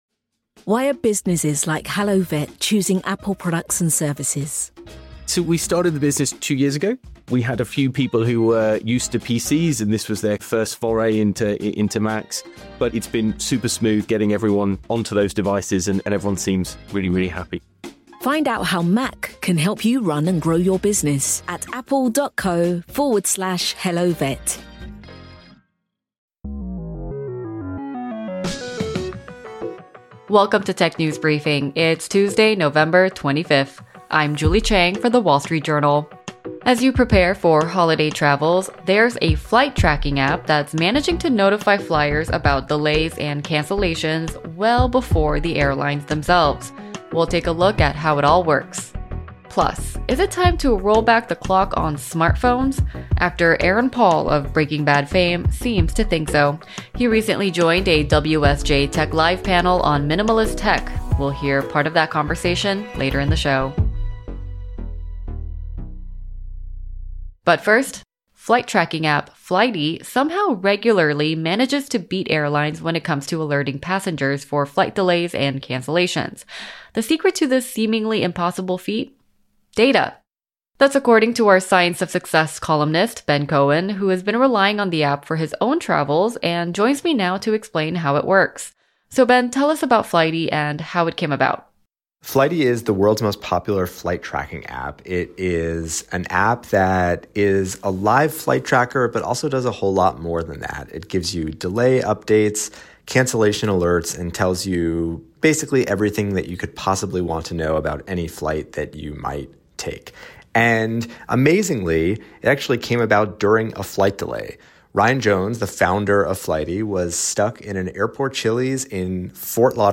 That's the radical idea behind Light, a company founded on the belief that our constant fight for attention has turned smartphones into an addiction. At WSJ Tech Live